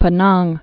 (pə-näng, pēnäng)